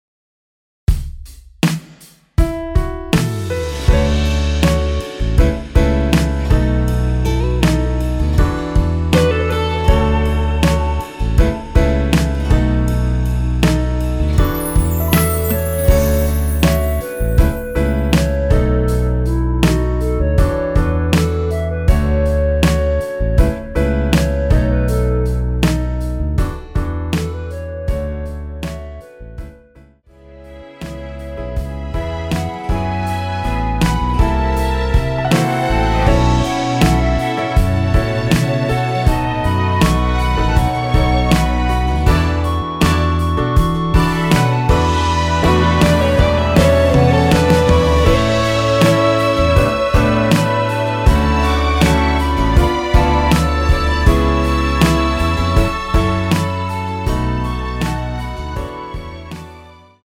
원키에서(+5)올린 멜로디 포함된 MR 입니다.(미리듣기 확인)
멜로디 MR이라고 합니다.
앞부분30초, 뒷부분30초씩 편집해서 올려 드리고 있습니다.
중간에 음이 끈어지고 다시 나오는 이유는